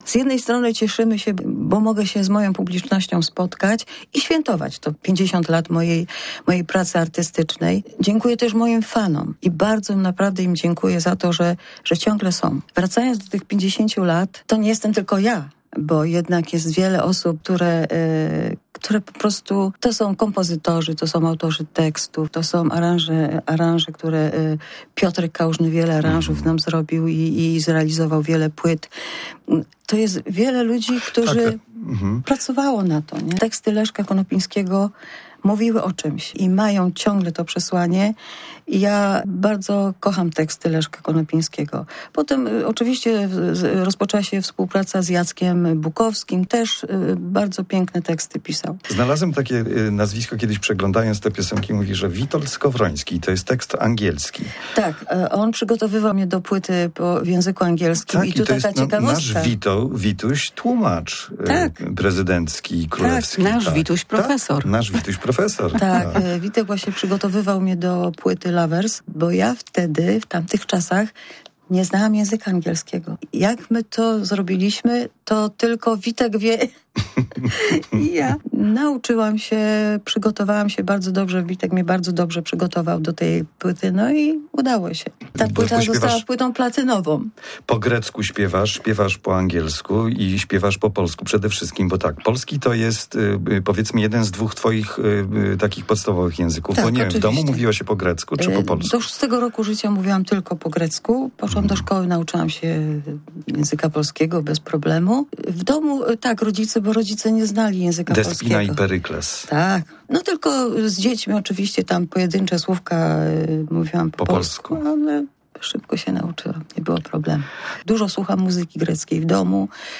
Z Eleni rozmawialiśmy w przedpołudniowym programie Mała Czarna, a ponieważ z jubileuszem łączy się wiele wspomnień i emocji, dlatego wracamy jeszcze do fragmentu tej rozmowy.
Zapis rozmowy: